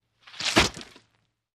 На этой странице собраны звуки копья: удары, скрежет, броски и другие эффекты.
Звук копья вонзаемого в тело человека